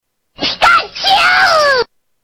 Звуки пикачу